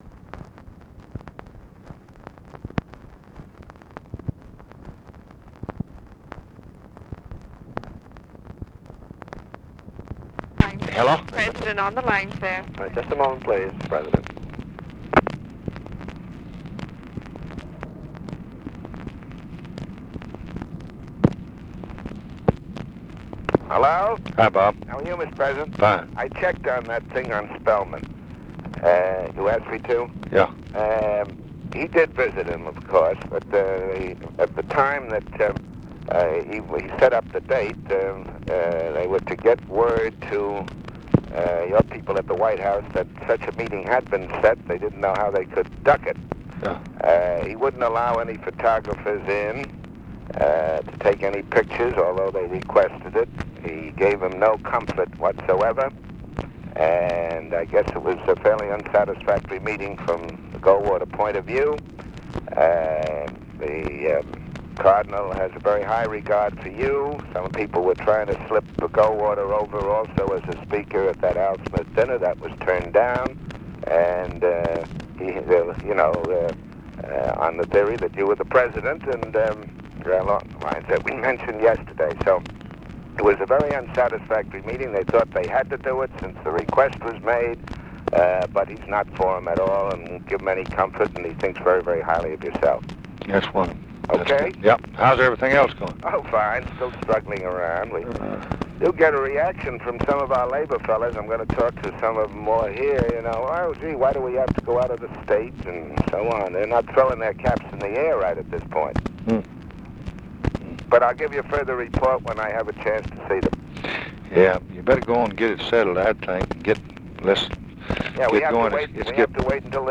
Conversation with ROBERT WAGNER, August 13, 1964
Secret White House Tapes